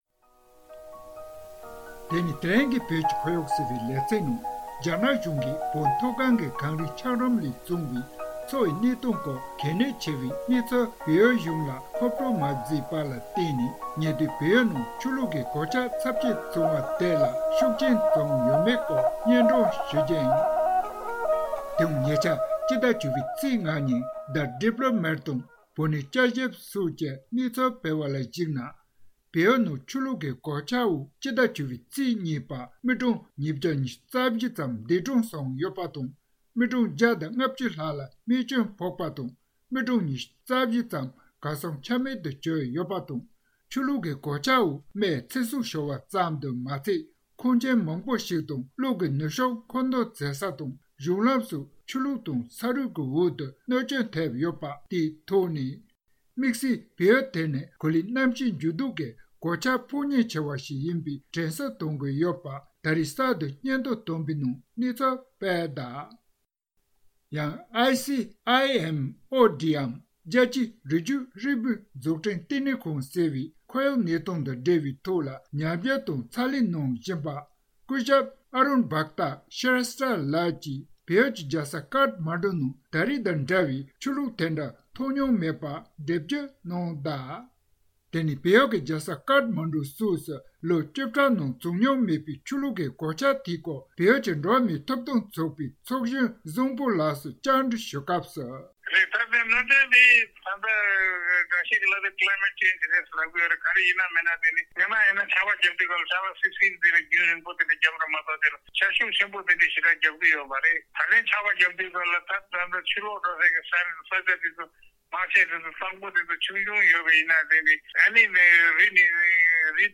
བཅའ་འདྲི་ཞུས་ཏེ་